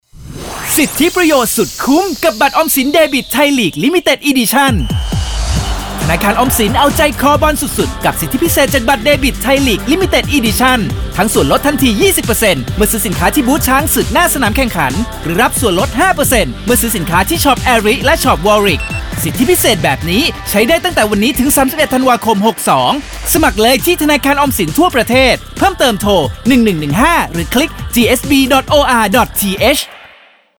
โฆษณาวิทยุ Archives - Government Savings Bank